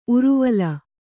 ♪ uruvala